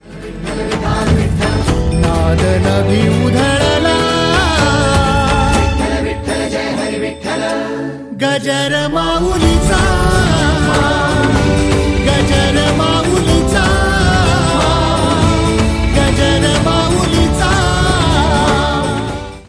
devotional kirtan
energetic and soulful voice
Devotional Songs